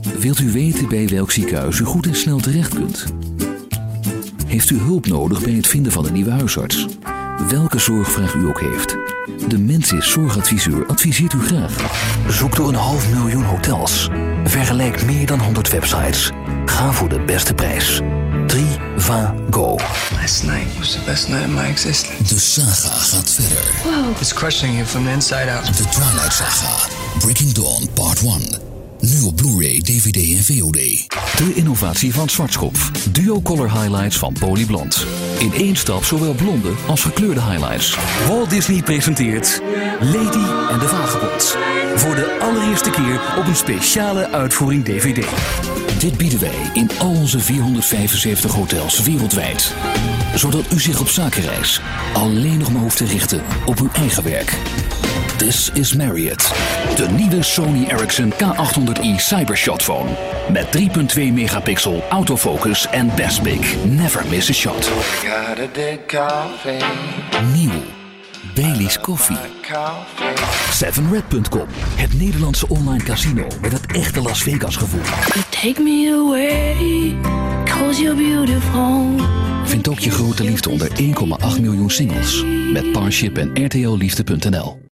Native speaker Male 30-50 lat
Nagranie lektorskie